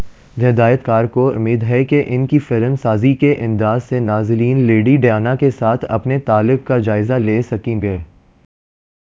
Spoofed_TTS/Speaker_14/274.wav · CSALT/deepfake_detection_dataset_urdu at main